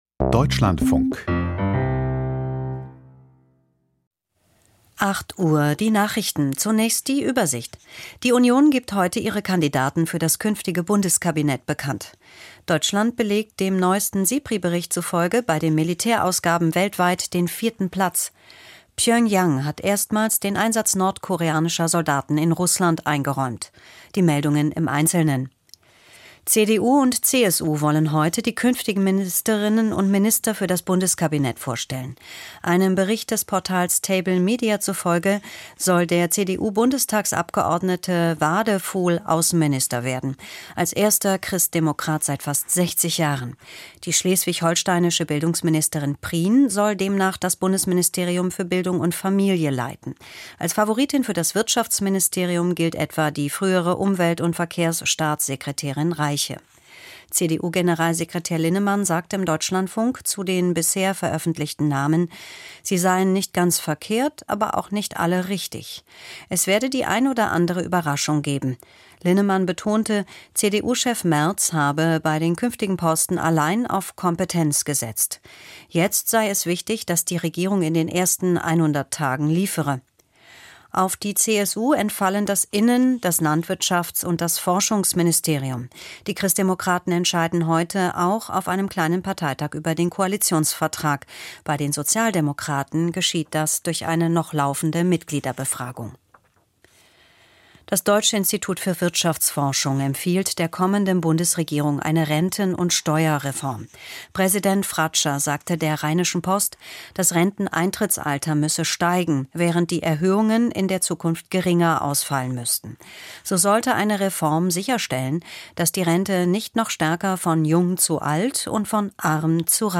Die Deutschlandfunk-Nachrichten vom 28.04.2025, 08:00 Uhr